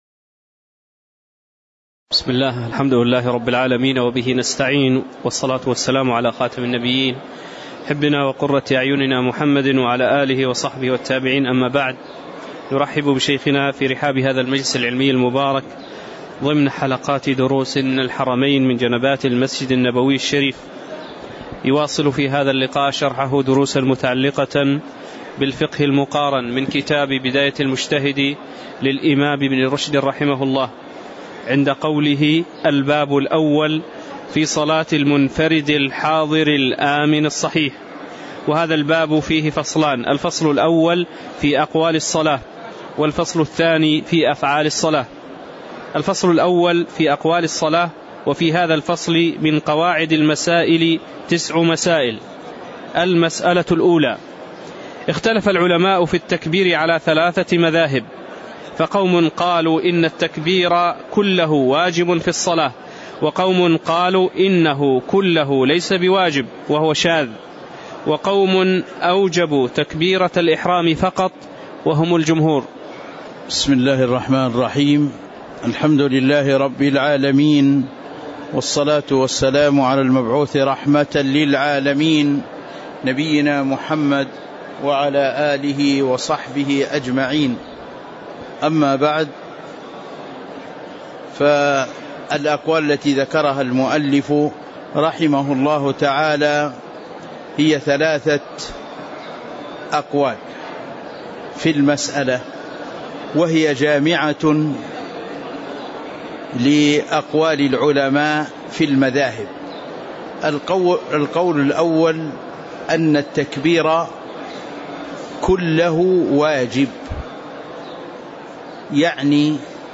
تاريخ النشر ٢٨ ربيع الأول ١٤٤١ هـ المكان: المسجد النبوي الشيخ